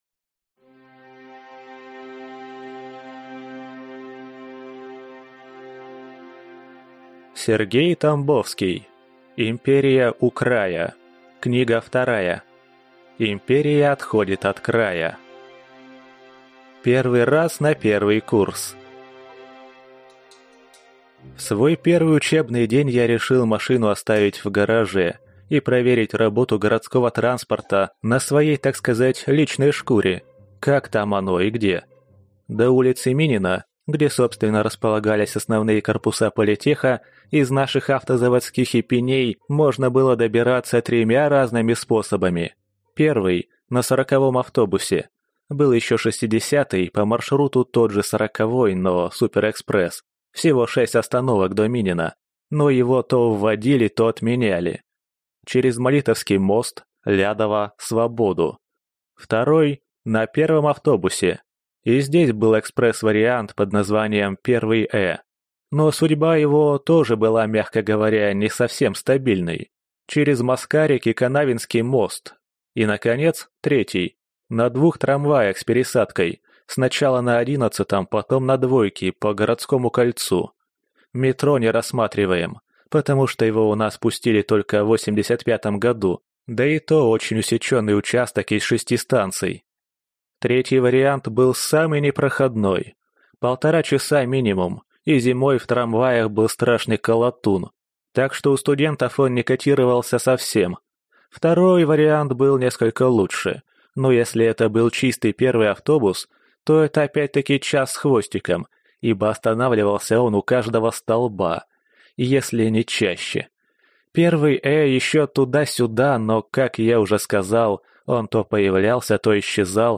Аудиокнига Империя отходит от края | Библиотека аудиокниг